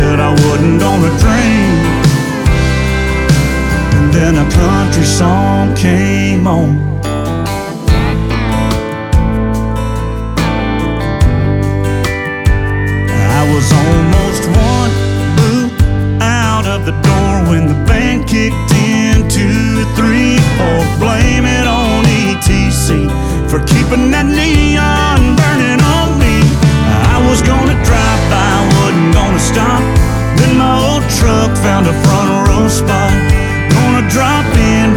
Жанр: Кантри